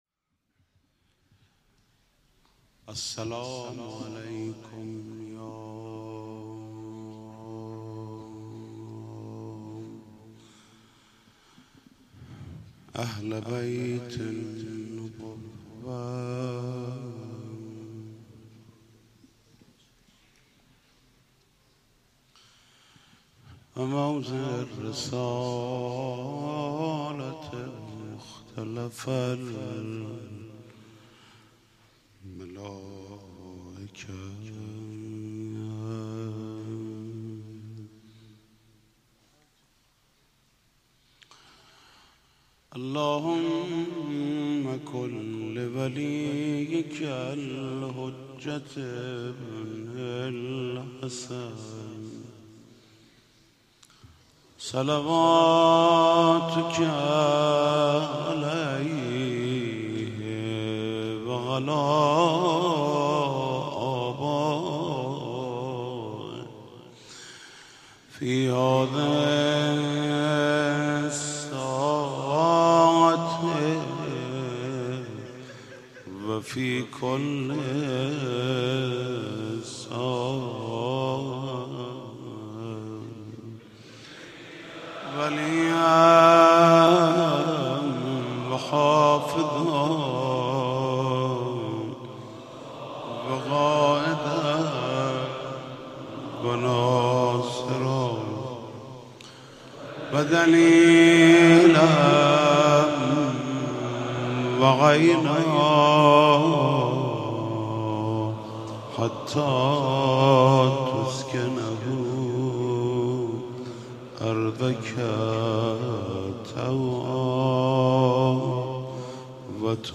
گلچین مداحی شهادت امام محمد باقر(ع